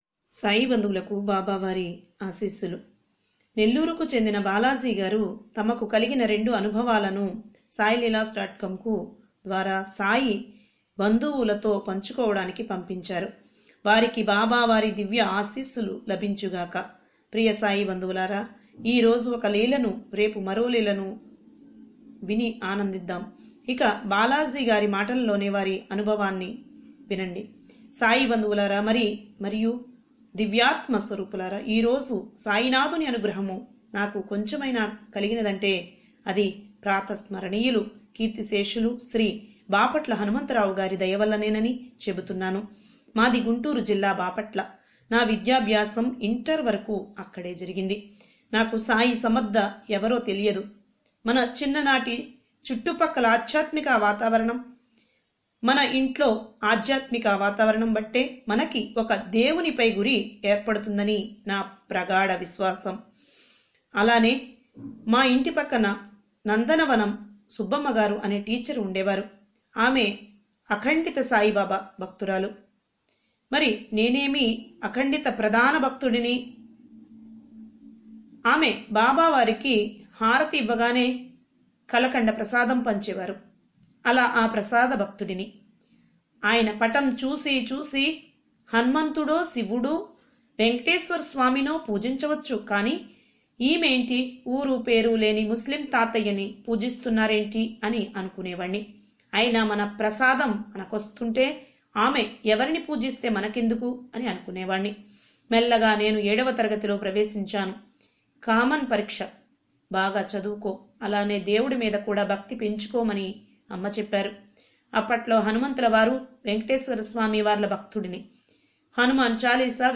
Sai Baba miracles in English,Hindi Miracles, Telugu, Sai Baba bhajans